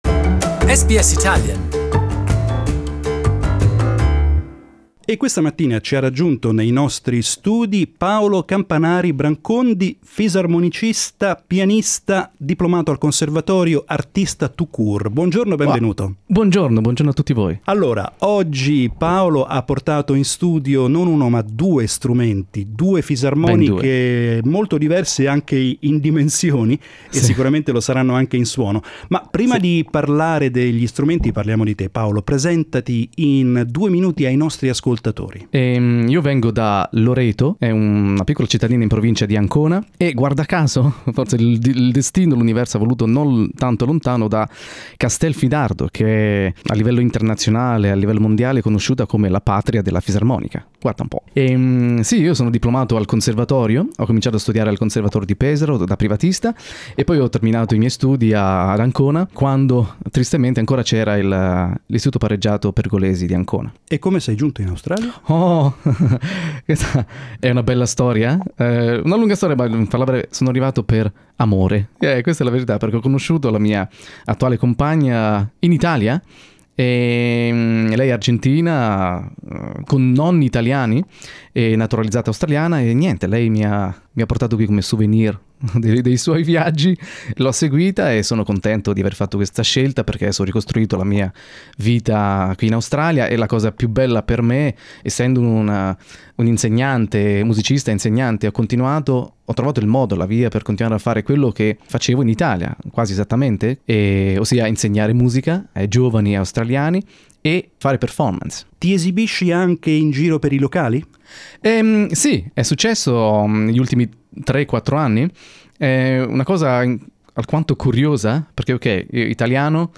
The accordion